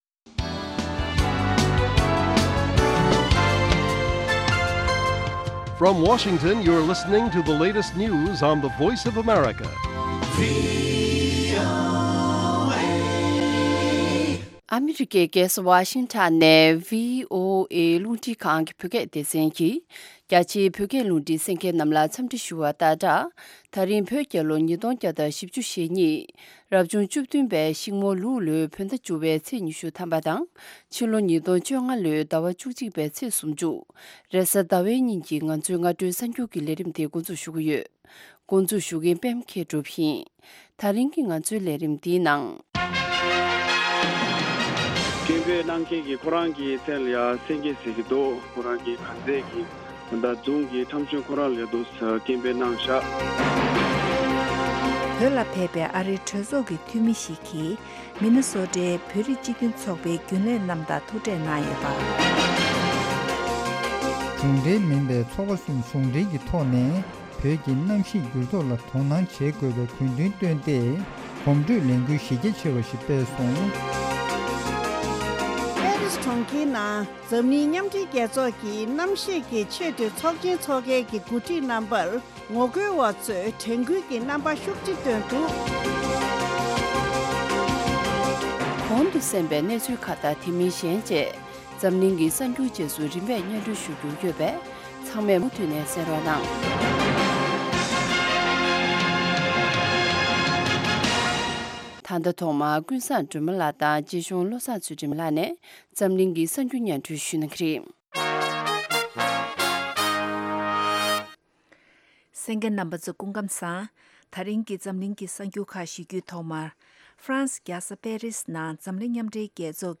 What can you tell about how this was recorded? Morning News Broadcast daily at 08:00 AM Tibet time, the Morning show is a lively mix of regional and world news, correspondent reports, and interviews with various newsmakers and on location informants.